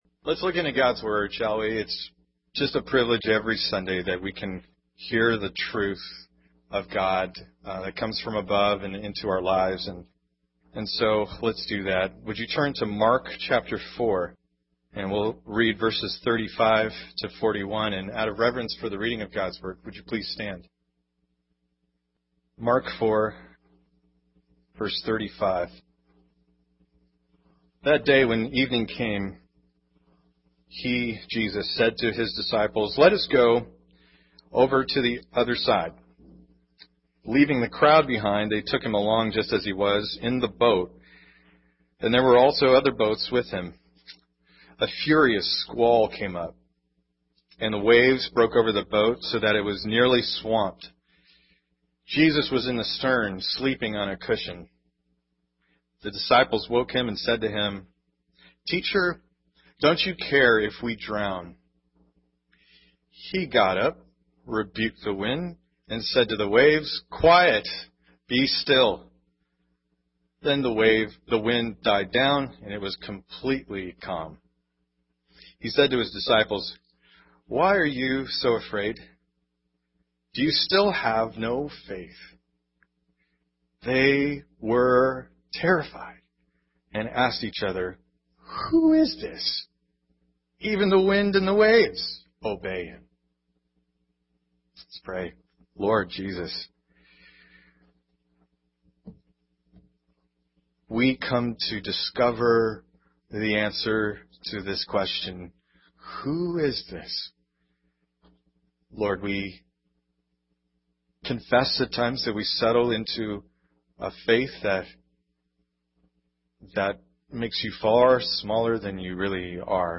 Sermons – 2010 | New Life Church, SF | Becoming.